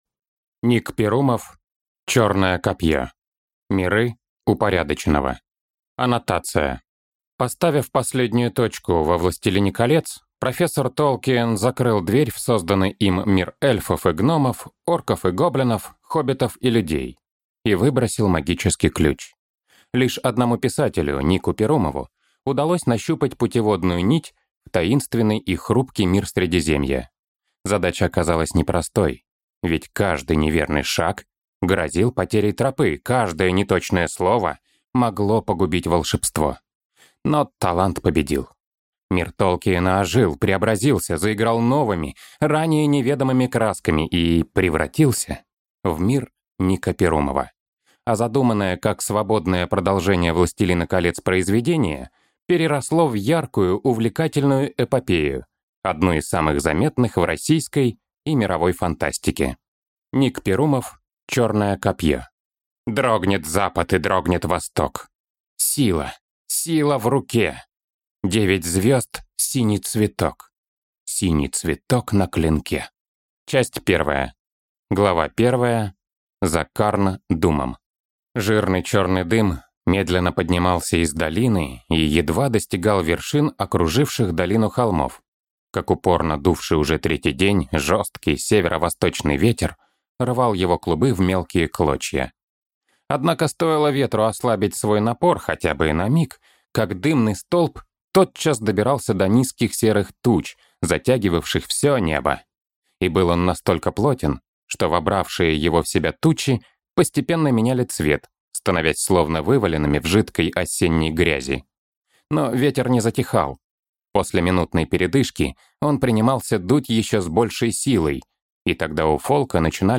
Аудиокнига Черное копье | Библиотека аудиокниг
Прослушать и бесплатно скачать фрагмент аудиокниги